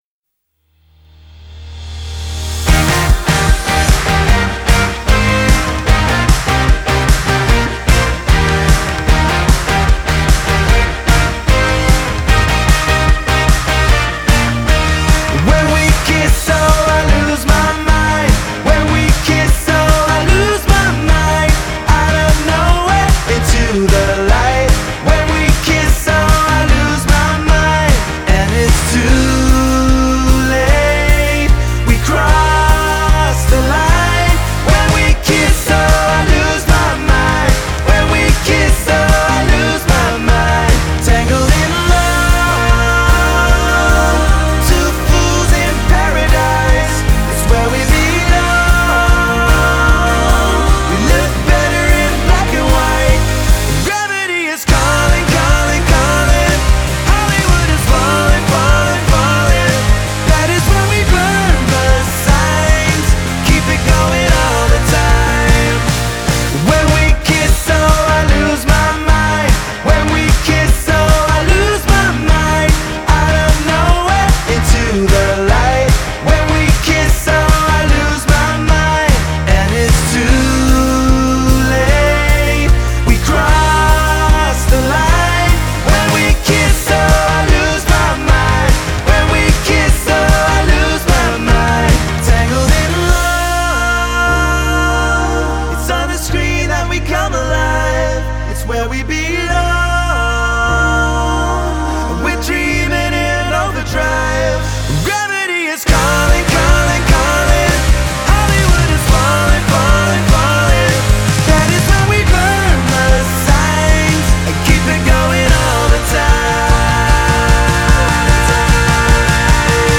is relentless in its hooky delivery